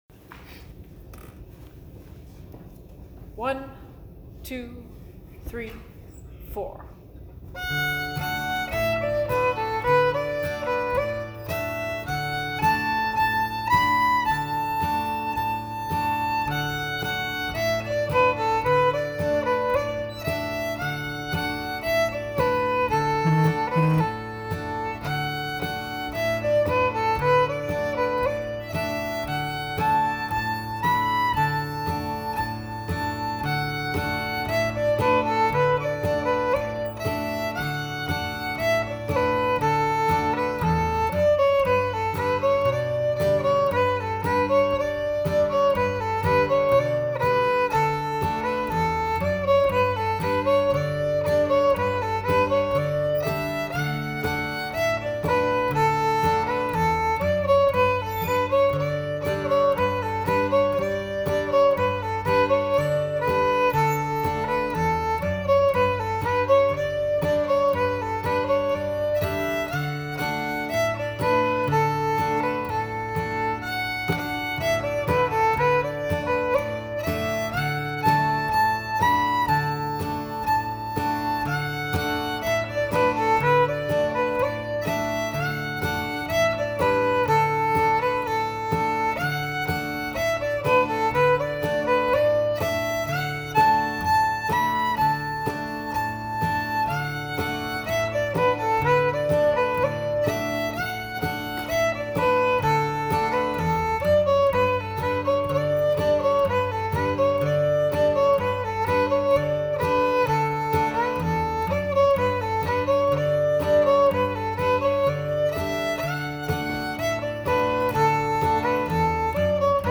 “Julianne Johnson” is a fiddle tune that was popularized by a recording of Galax, Virginia-area fiddler Emmett Lundy in 1941.